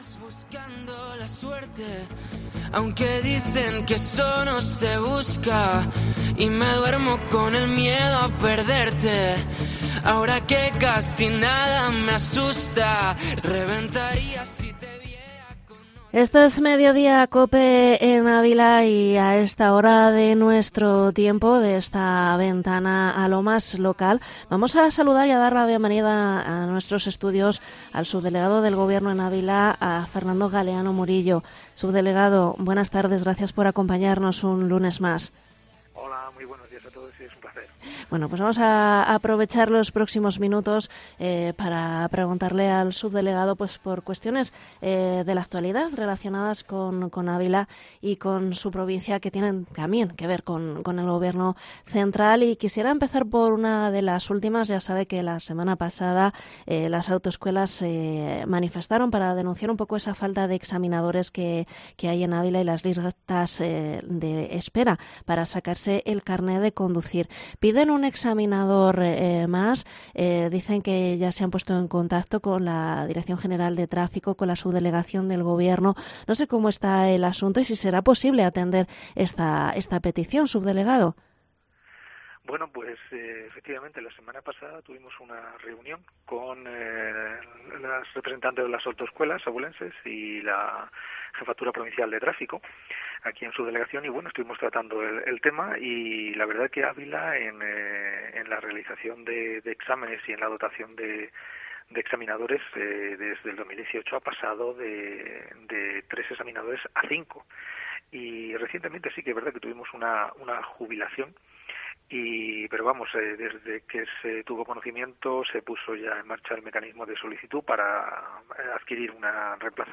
ENTREVISTA al subdelegado del Gobierno en Ávila, Fernando Galeano en COPE ÁVILA 19/02/2024